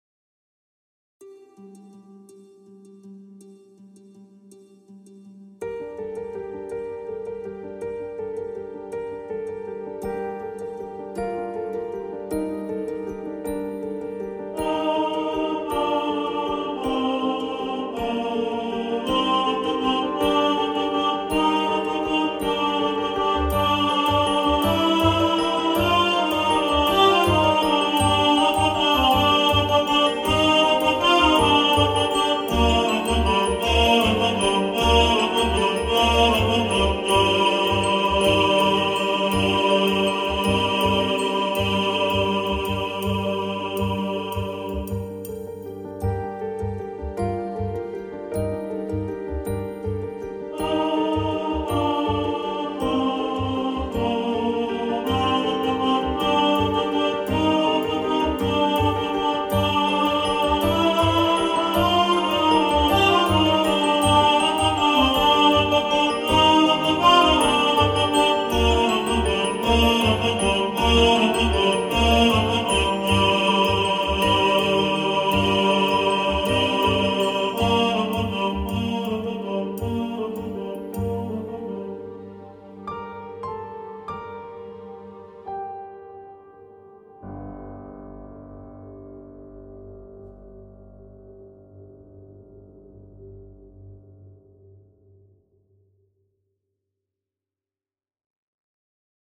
Carol Of The Bells – Tenor | Ipswich Hospital Community Choir
Carol-Of-The-Bells-Tenor.mp3